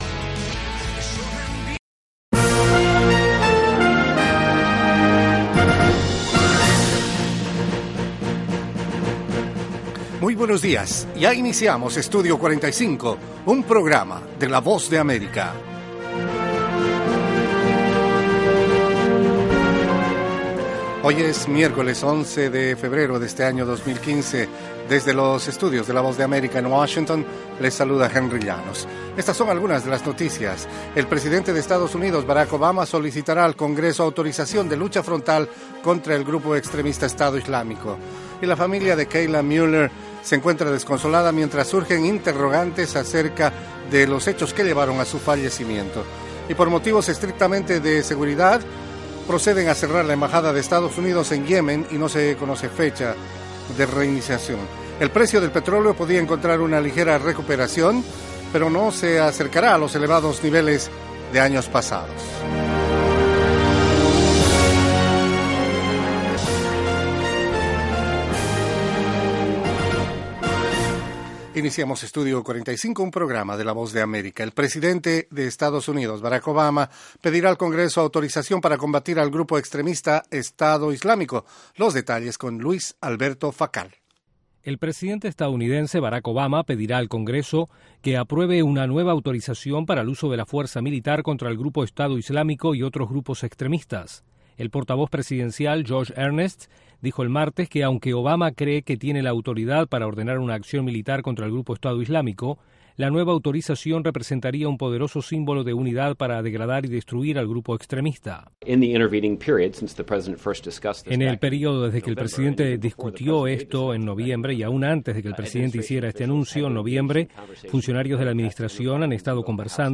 Treinta minutos de la actualidad noticiosa de Estados Unidos con análisis y entrevistas.